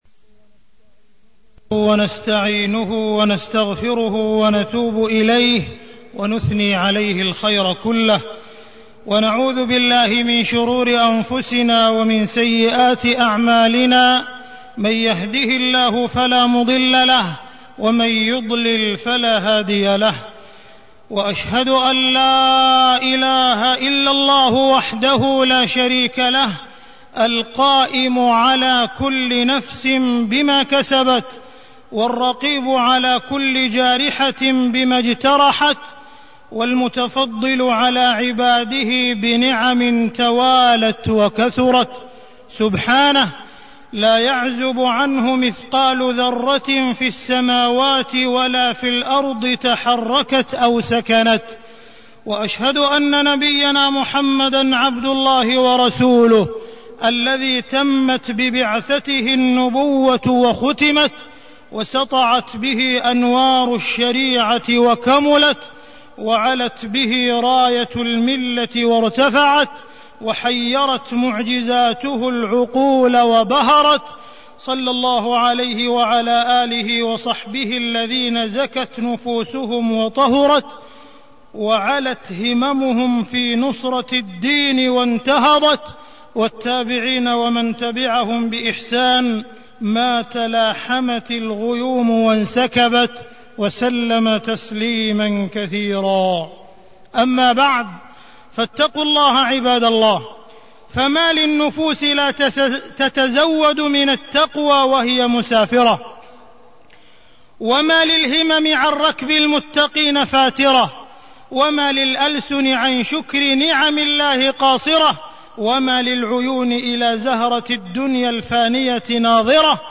تاريخ النشر ١ ربيع الثاني ١٤٢٢ هـ المكان: المسجد الحرام الشيخ: معالي الشيخ أ.د. عبدالرحمن بن عبدالعزيز السديس معالي الشيخ أ.د. عبدالرحمن بن عبدالعزيز السديس الاجازة والسفر